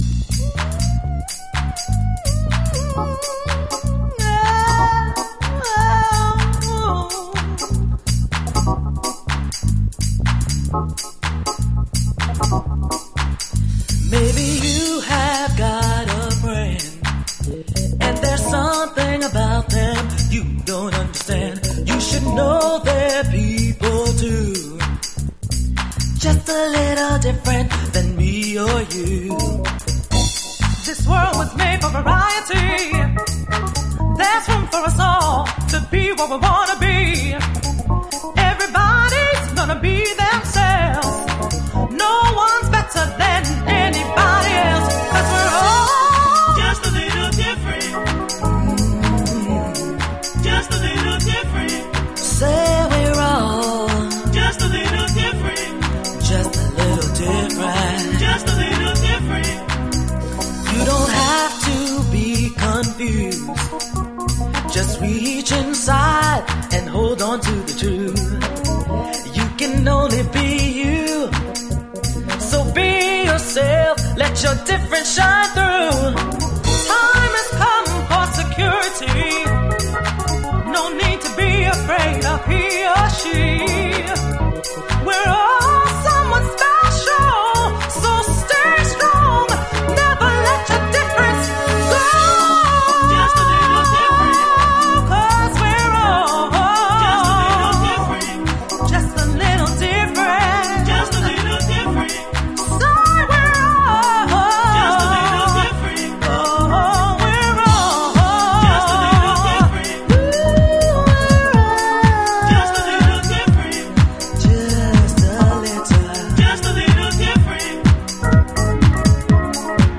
エモーショナルに歌い上げるフロア賛歌。
ゴスペルハウス古典。
Vocal Dub